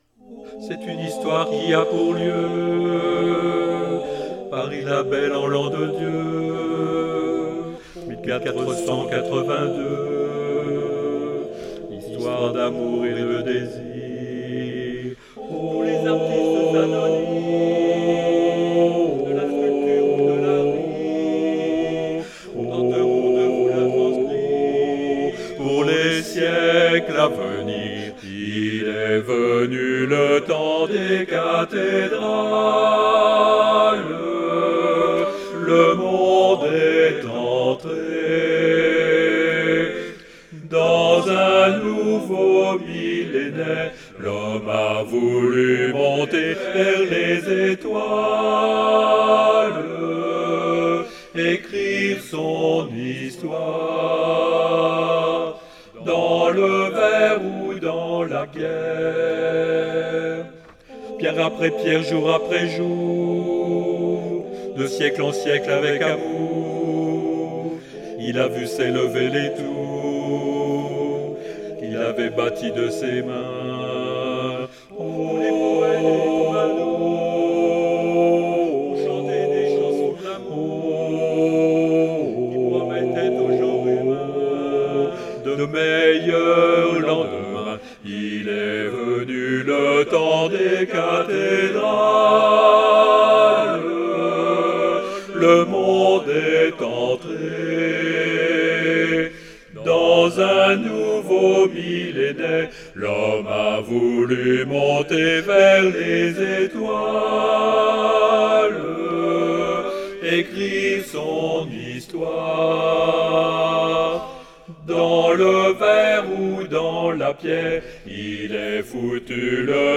Alto
à 4 voix